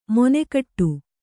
♪ mone kaṭṭu